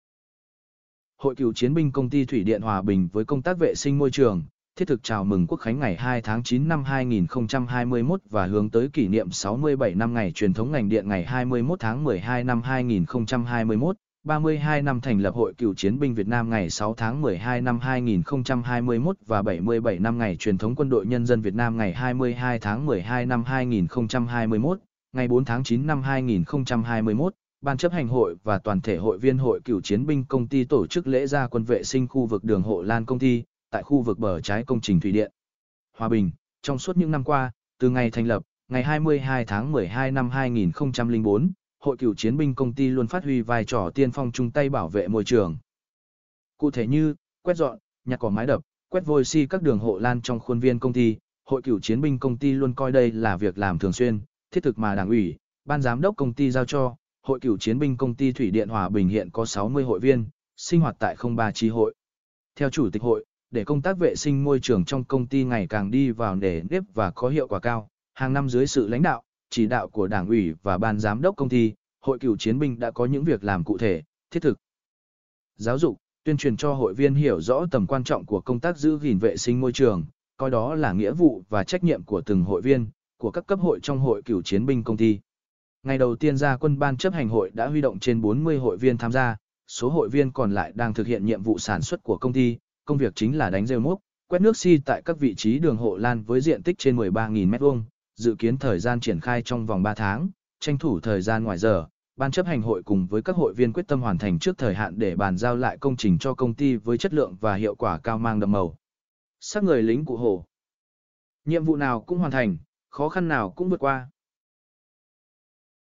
mp3-output-ttsfreedotcom-7.mp3